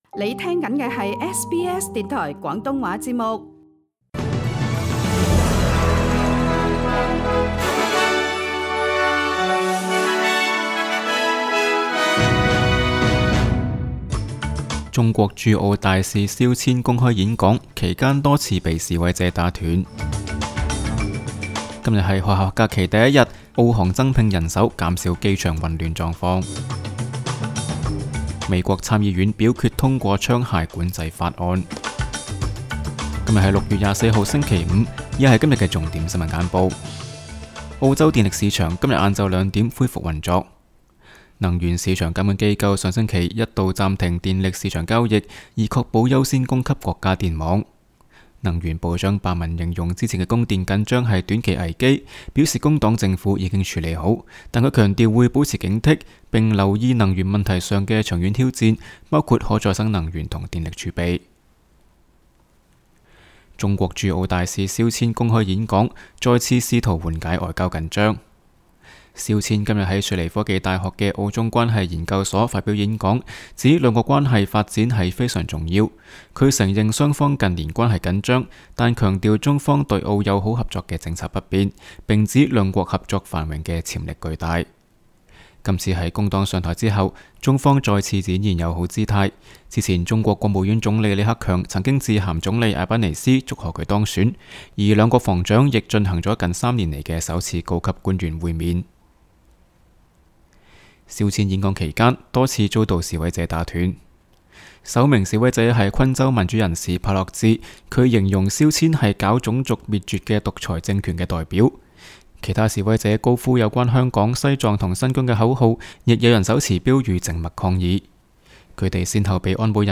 SBS 新闻简报（6月23日）
SBS 廣東話節目新聞簡報 Source: SBS Cantonese